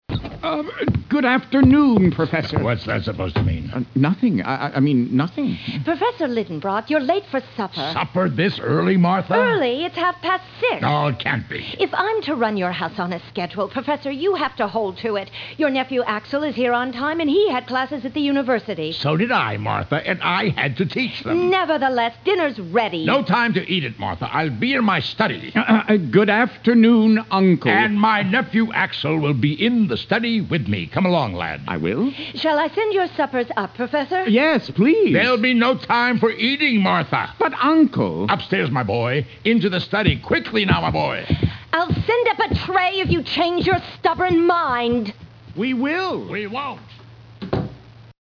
Journey to the Center of the Earth Audio book